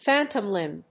アクセントphántom límb